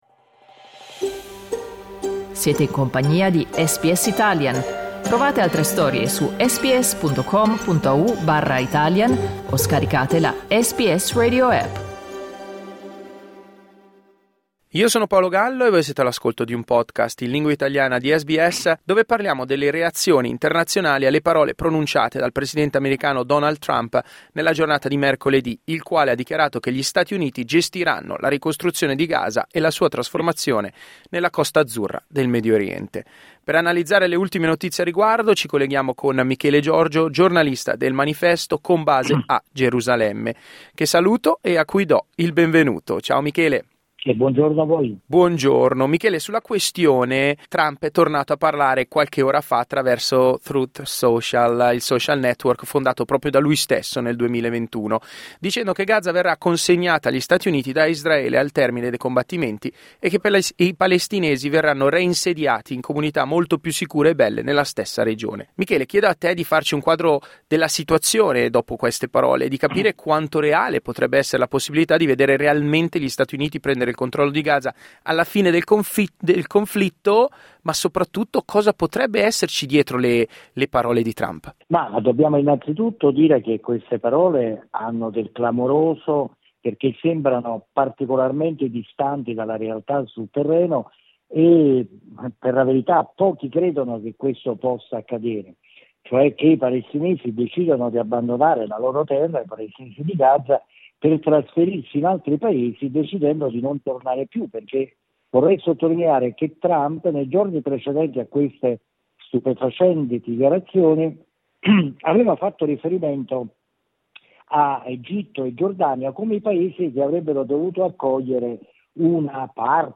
Ascolta il resoconto del corrispondente